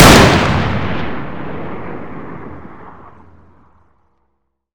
m82_fire1.wav